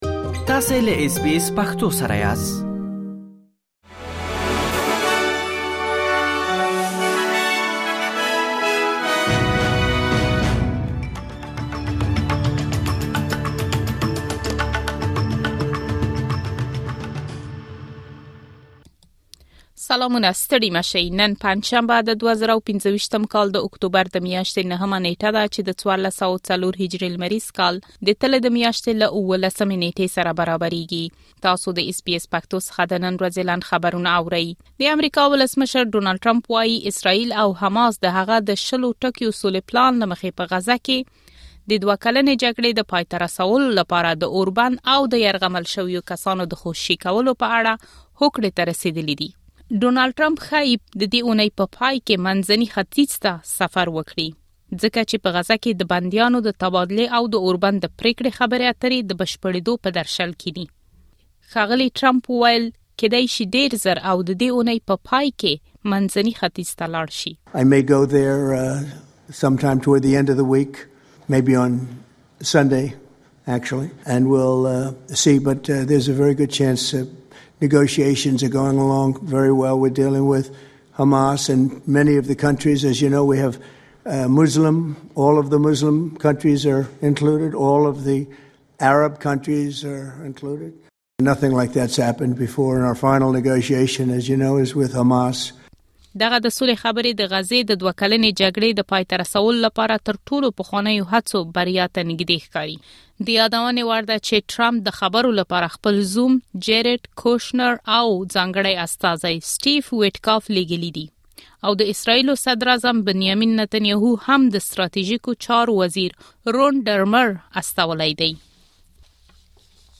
د اس بي اس پښتو د نن ورځې لنډ خبرونه|۹ اکتوبر ۲۰۲۵